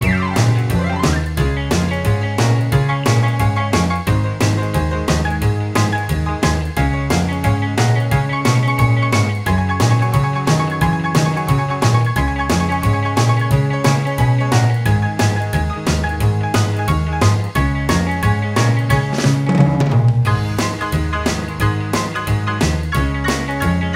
Minus Lead Guitar Rock 'n' Roll 3:01 Buy £1.50